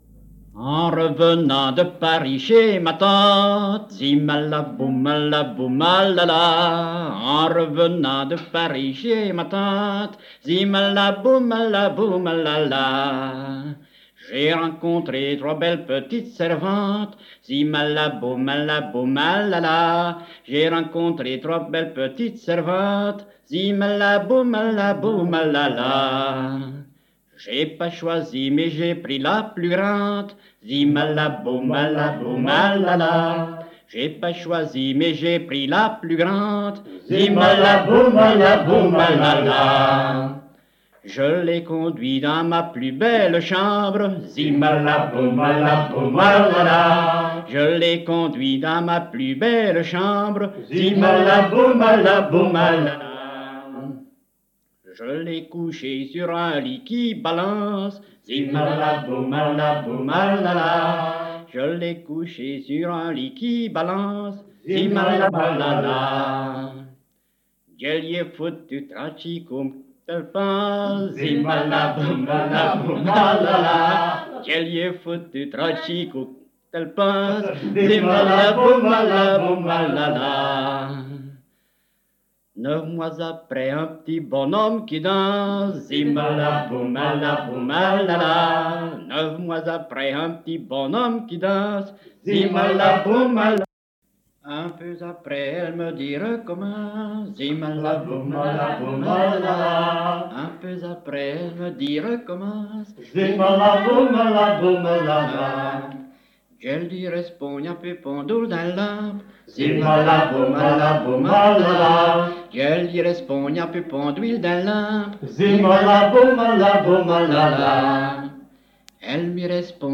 Genre : chant
Type : chanson narrative ou de divertissement
Interprète(s) : Anonyme (homme)
Lieu d'enregistrement : Morville
Support : bande magnétique
Chanson plaisante.